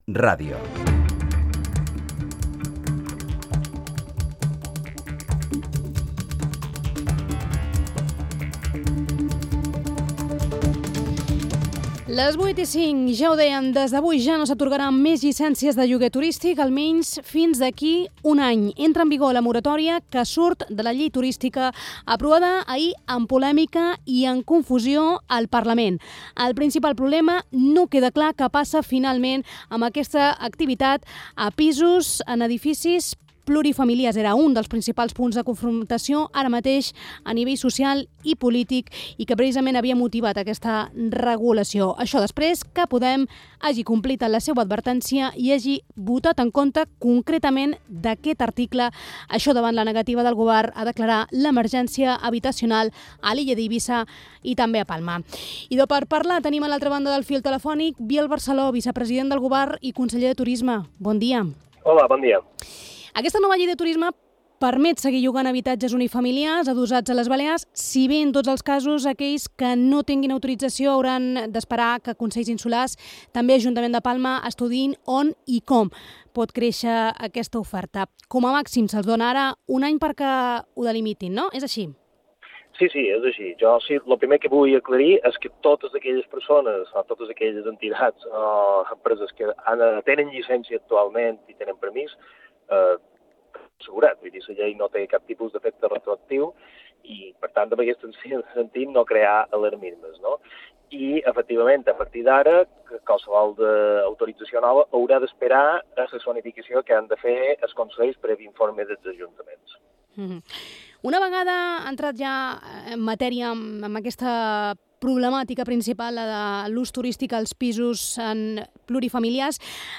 El vicepresident i conseller de Turisme, Biel Barceló , en declaracions a IB3 Ràdio després de la modificació de la Llei del turisme que s’ha aprovat aquest dimarts al Parlament , ha explicat que hauran de consultar els serveis jurídics per aclarir aquest punt.